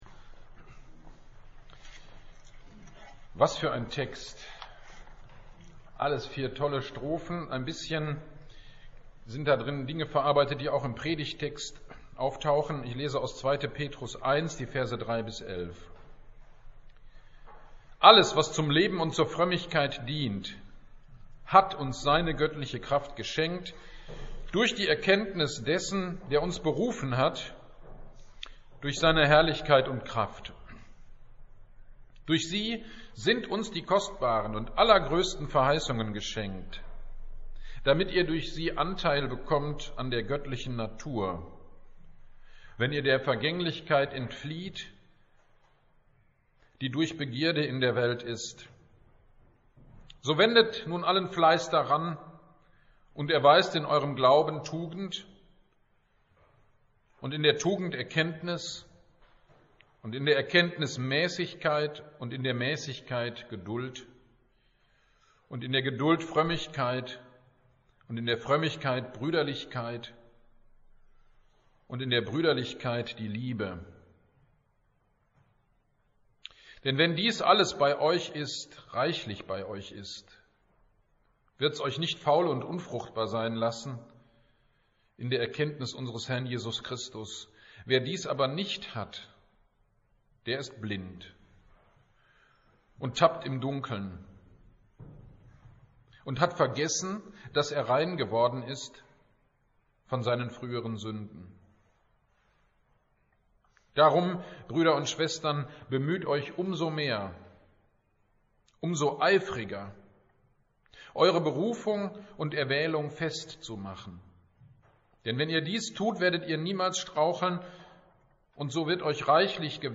27.02.22 Predigt zu 2. Petrus 1.3-11